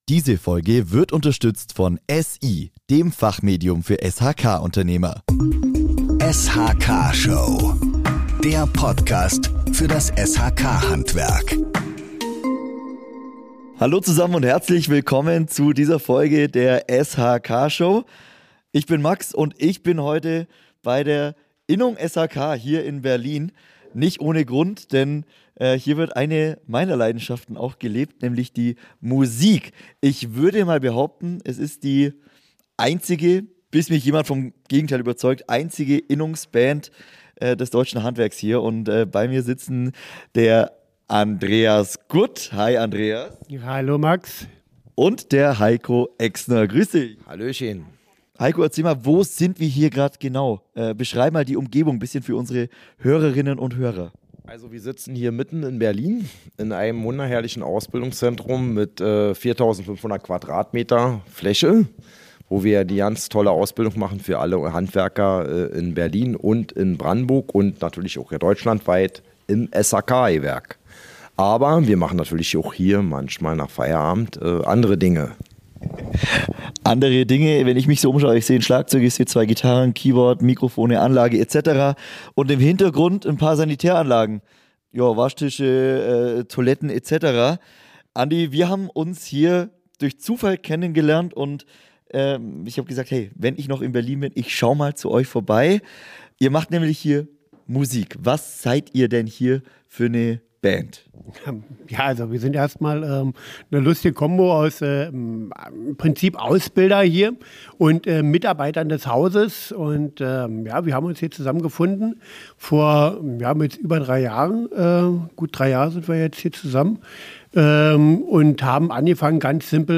Die Band hat sich dem Rock und Punk-Rock verschrieben und kreiert eigene Songs – teils mit direktem Bezug zum Handwerk.
Und Kostprobe des Songs hört ihr natürlich auch in dieser Folge!